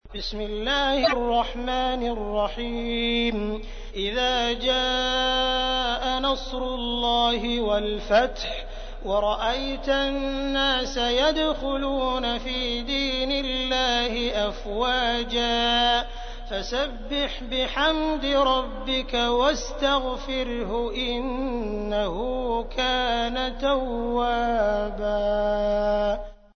تحميل : 110. سورة النصر / القارئ عبد الرحمن السديس / القرآن الكريم / موقع يا حسين